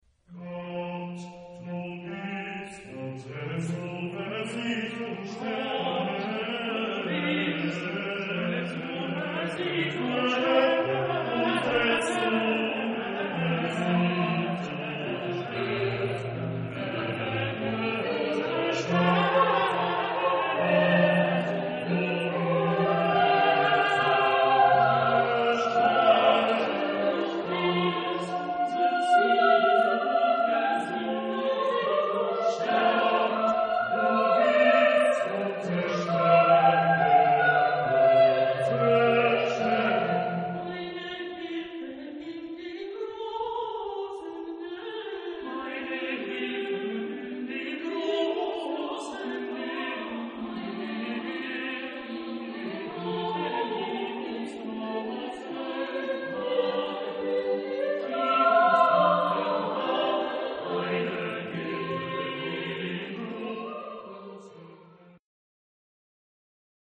Genre-Style-Forme : Sacré ; Motet ; Psaume
Type de choeur : SSATB  (5 voix mixtes )
Tonalité : fa majeur